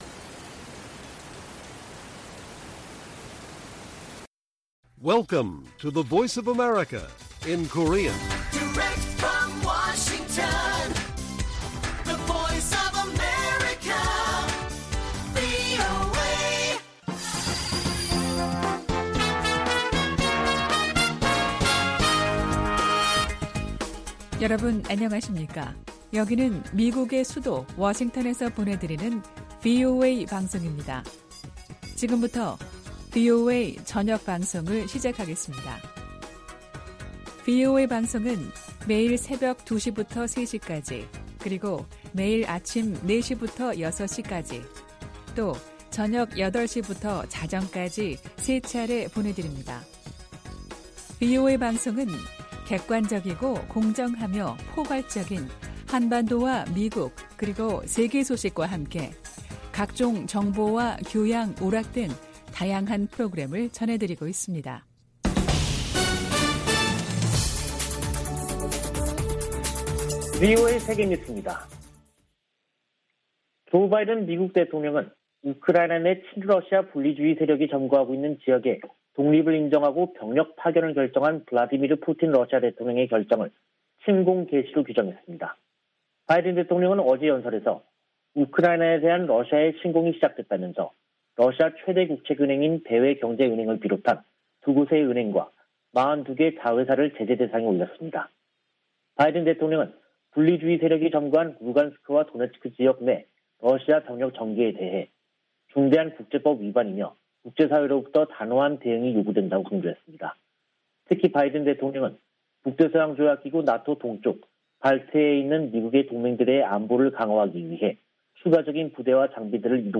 VOA 한국어 간판 뉴스 프로그램 '뉴스 투데이', 2022년 2월 23일 1부 방송입니다. 최근 미국이 B-52 전략폭격기를 괌에 전개한 것은 인도태평양 역내 공격 억지를 위한 것이라고 기지 당국자가 밝혔습니다. 미국과 일본이 탄도미사일 방어에 초점을 둔 연례 연합훈련에 돌입했습니다. 한국인 70% 이상이 자체 핵무기 개발을 지지하는 것으로 나타났습니다.